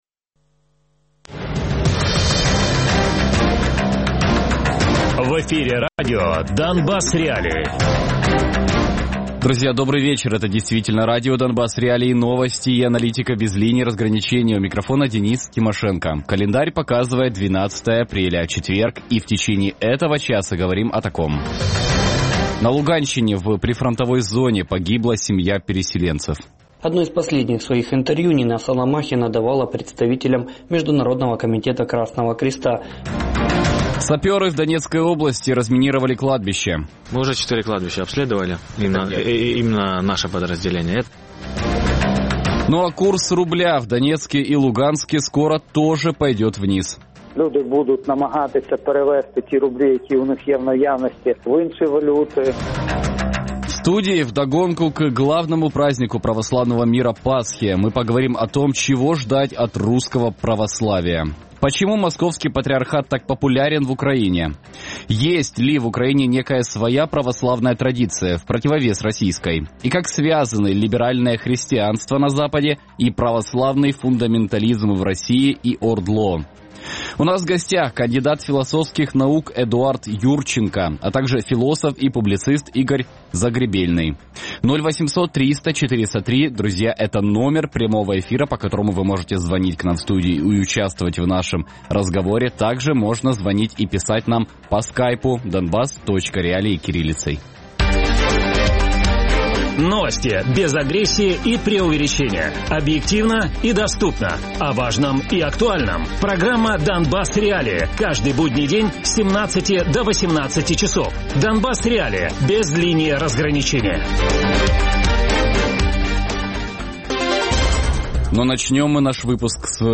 публіцист і філософ Радіопрограма «Донбас.Реалії» - у будні з 17:00 до 18:00.